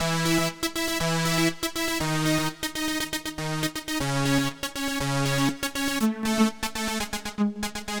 Power Pop Punk Keys 03.wav